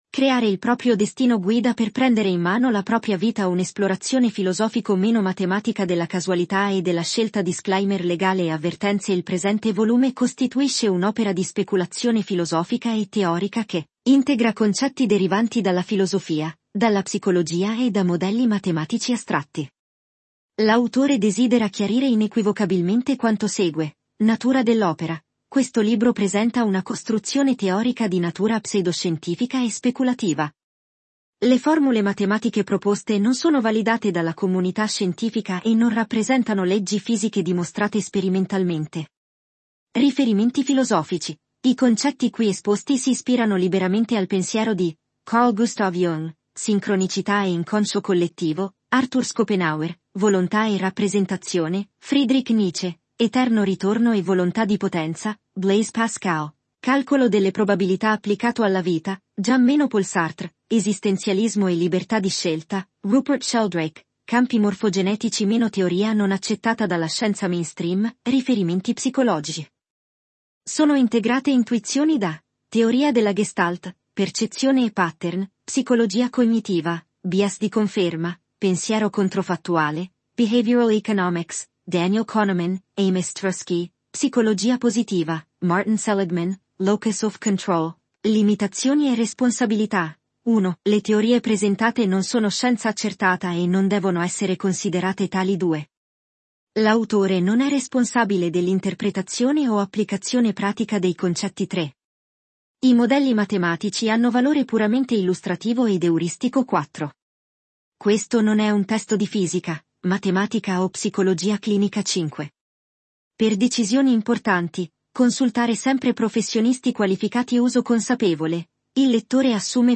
Versione integrale • Qualità HD
audiolibro-destino.mp3